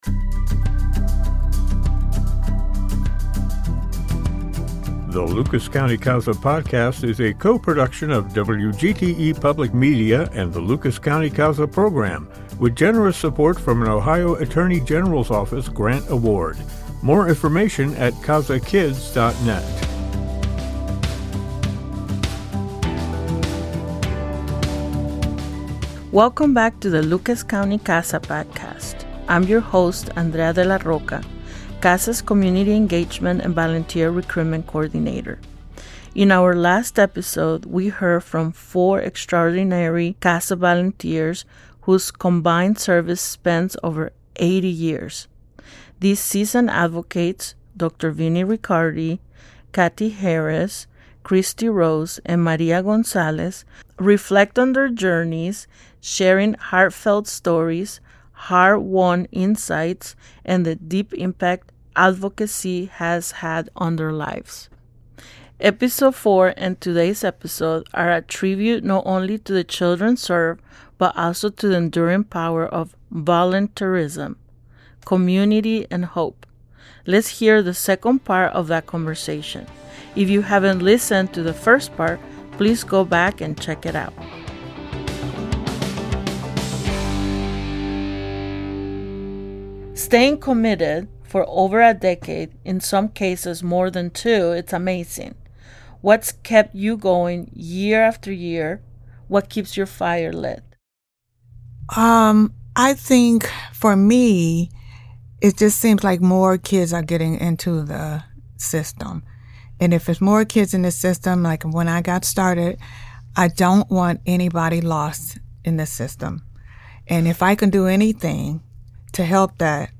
Part two of our discussion with CASA veterans as they share highlights from their journey over the years.